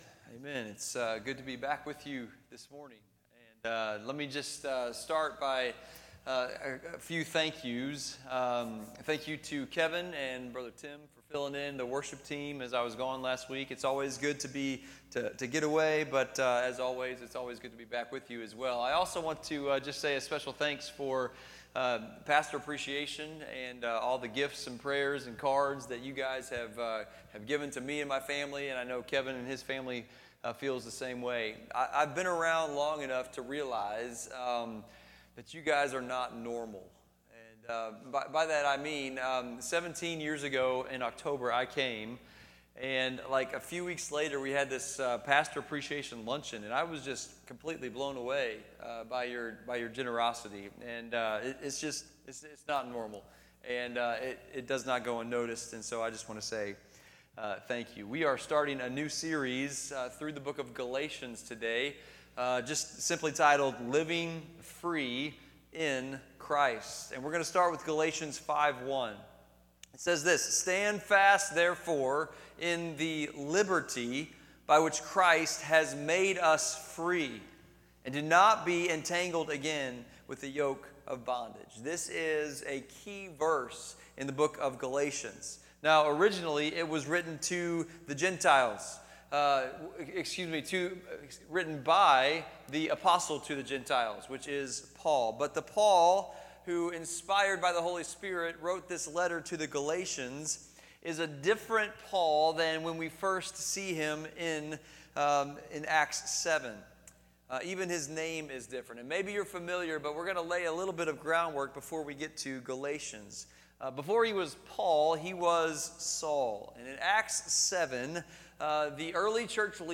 FBC Potosi - Sunday Service